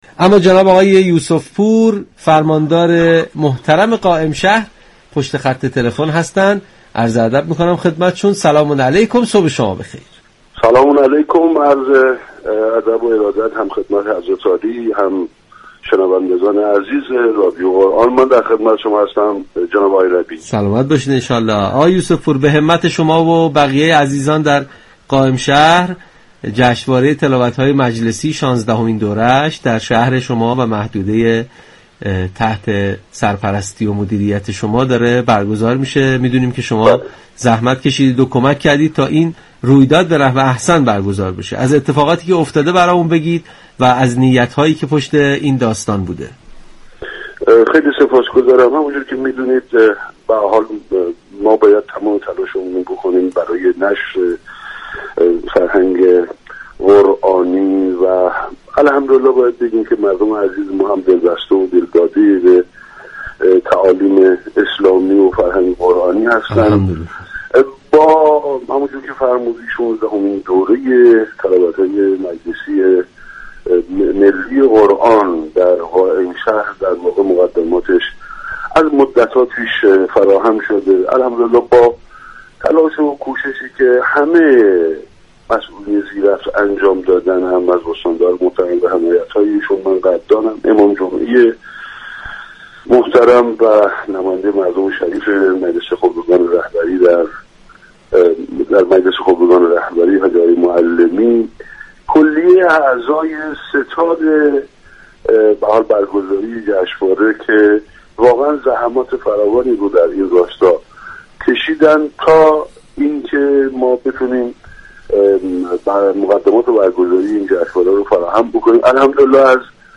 به گزارش پایگاه اطلاع رسانی رادیو قرآن ؛یوسف پور فرماندار قائم شهر در گفتگو با برنامه تسنیم رادیو قرآن گفت: فینال مرحله كشوری شانزدهمین جشنواره تلاوت‌های قرآنی با حضور قاریان ممتاز كشور در قالب شش گروه طی روزهای سه‌شنبه و چهارشنبه در دو نوبت ساعت‌های 14و 18 در سه شهر قائمشهر، ساری و گرگان برگزار شد.